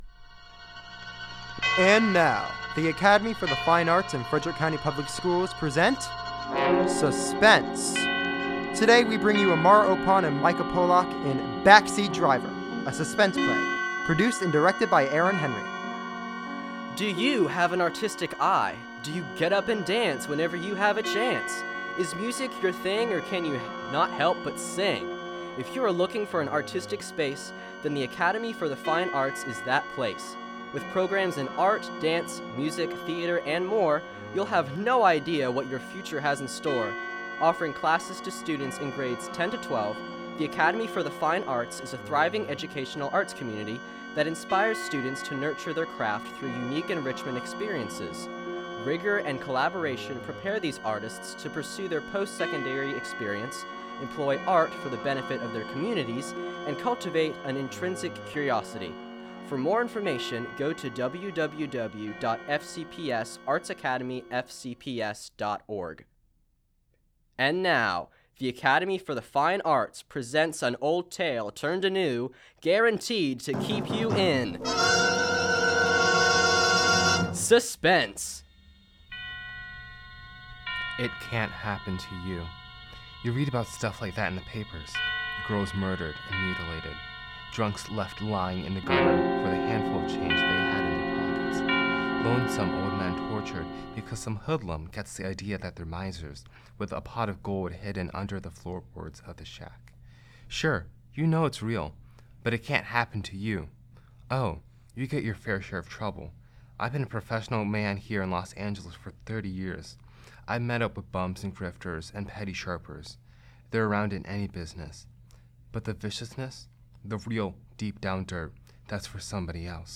A Suspence Play. Joe and Ellie take a night for themselves to see a movie, but on the way home, they are intruded upon by a murderer hidden in the backseat.